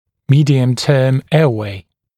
[‘miːdɪəm-tɜːm ‘eəweɪ][‘ми:диэм-тё:м ‘эауэй]средний отдел дыхательных путей